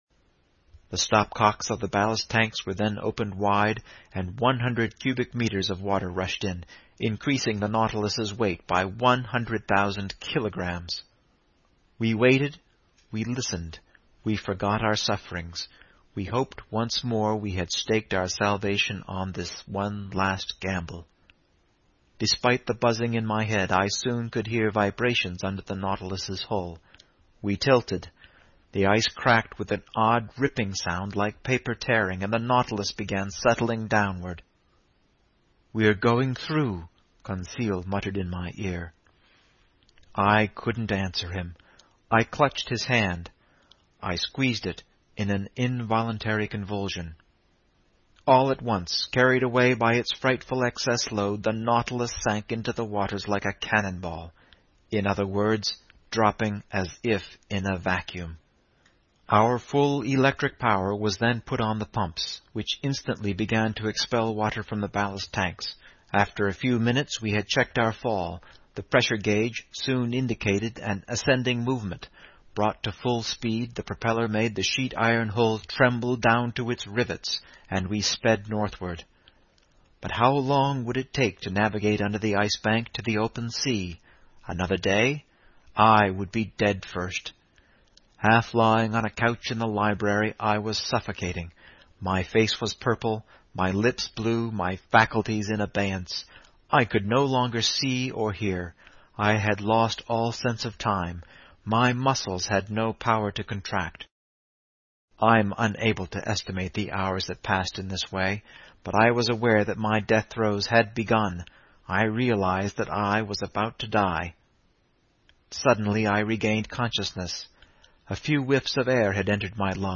英语听书《海底两万里》第476期 第29章 缺少空气(8) 听力文件下载—在线英语听力室